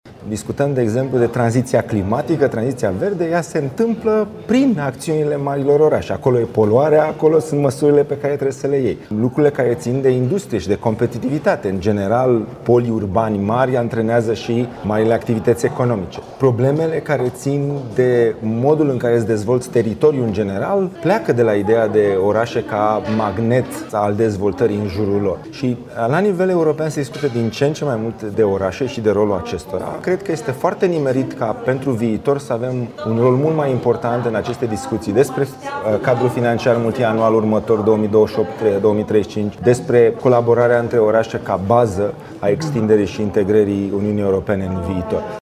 La Timișoara Cities Summit, ministrul Investițiilor și Proiectelor Europene a subliniat că dezvoltarea UE depinde în mare măsură de zonele urbane.
Prezent la Timișoara Cities Summit, Dragoș Pîslaru subliniază că principalele elemente care țin de dezvoltarea UE sunt concentrate în zonele urbane.